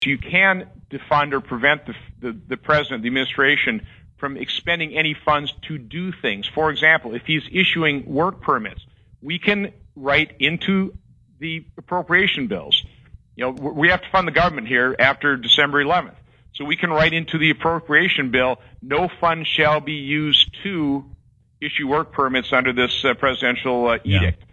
Senator Johnson gave these answers during an interview on Thursday, Nov. 20, with WSAU News/Talk AM 550.